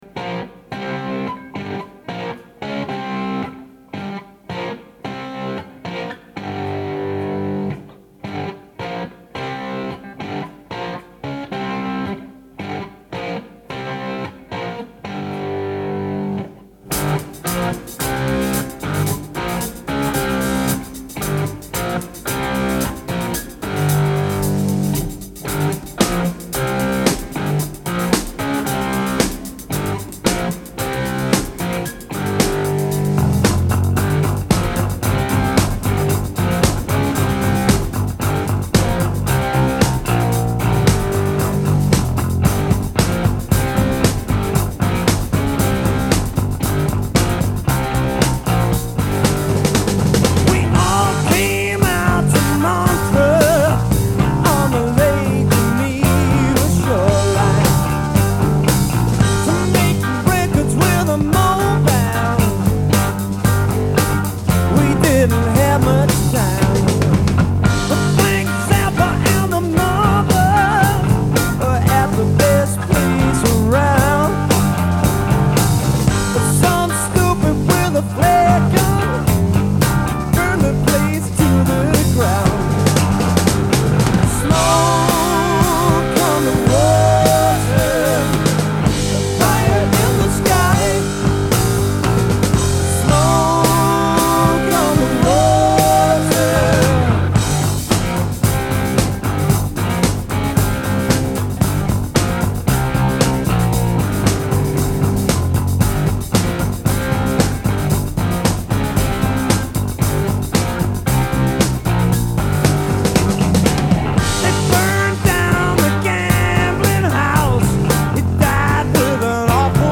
Rock 70er